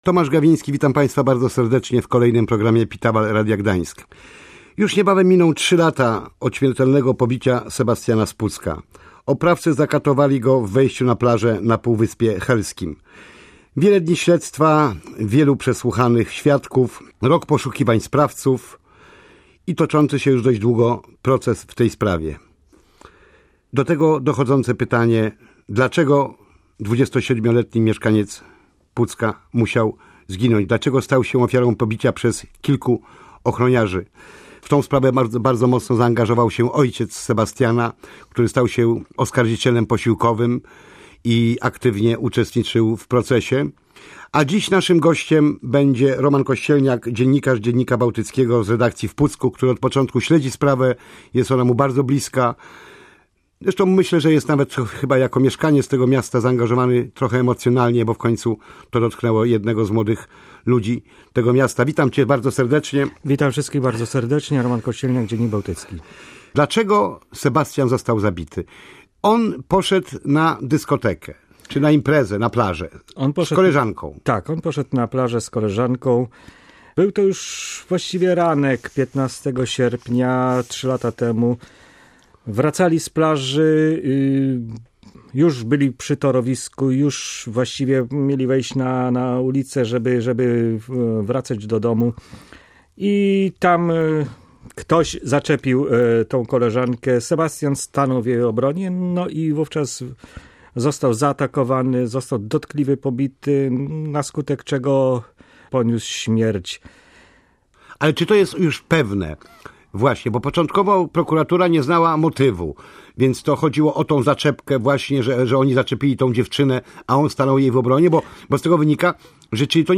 rozmawiał w programie Pitawal Radia Gdańsk